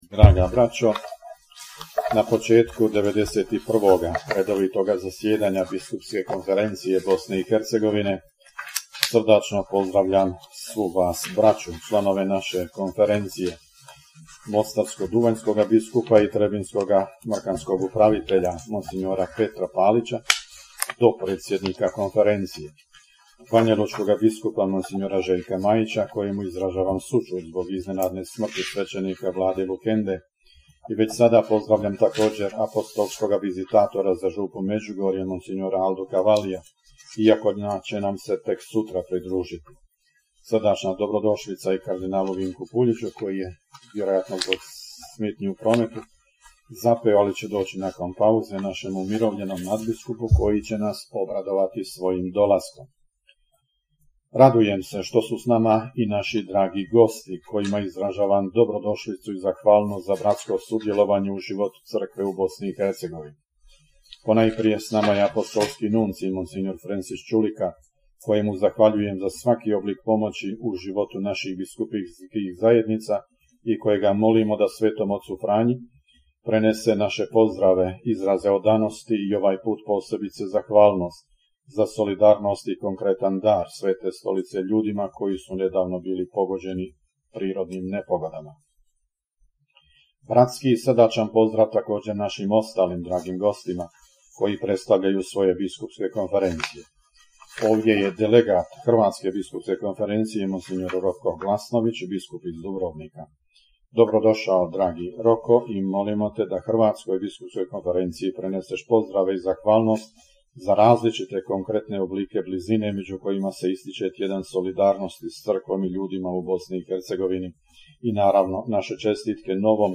Video: Pozdrav nadbiskupa Vukšića na početku 91. redovitog zasjedanja Biskupske konferencije Bosne i Hercegovine
Biskupska konferencija Bosne i Hercegovine započela je svoje 91. redovito zasjedanje u prostorijama Nadbiskupske rezidencije u Sarajevu, 4. studenog 2024. pod predsjedanjem nadbiskupa metropolita vrhbosanskog i apostolskog upravitelja Vojnog ordinarijata u BiH mons. Tome Vukšića, predsjednika BK BiH. Na početku zasjedanja sve je pozdravio nadbiskup Vukšić, a njegov pozdrav prenosimo u cijelosti: